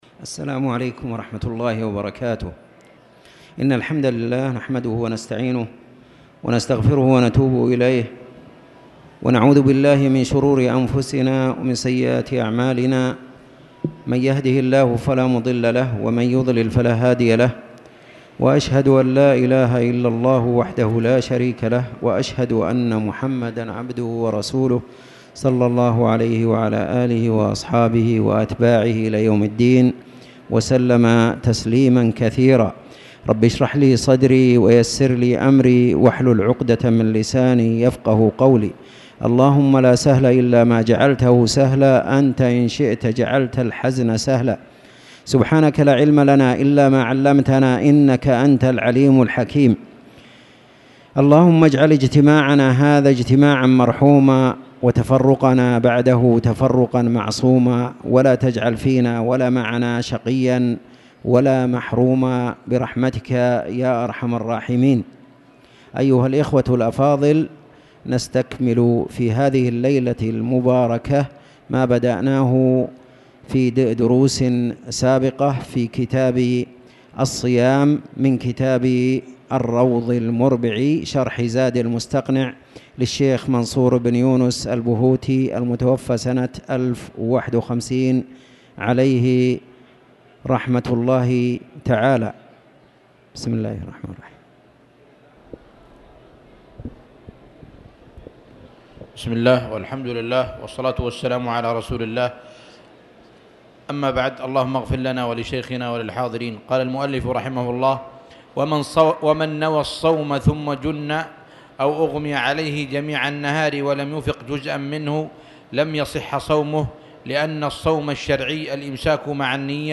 تاريخ النشر ٢٠ ربيع الأول ١٤٣٨ هـ المكان: المسجد الحرام الشيخ